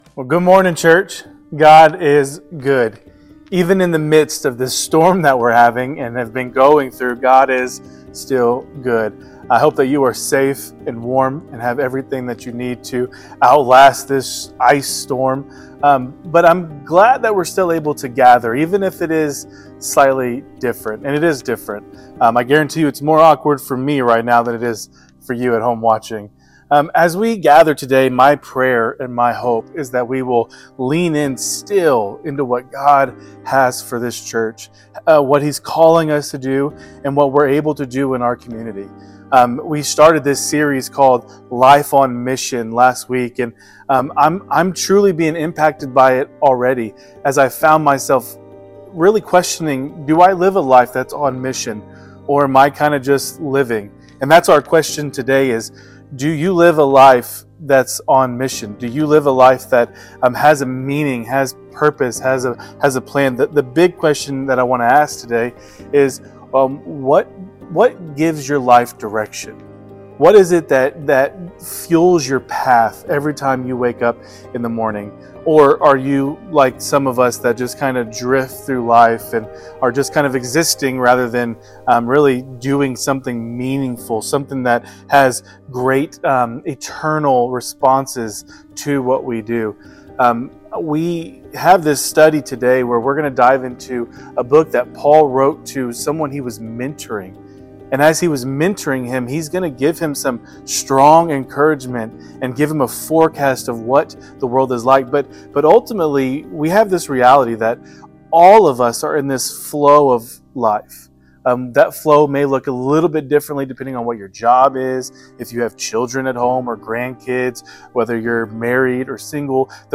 Sermons | Christian Covenant Fellowship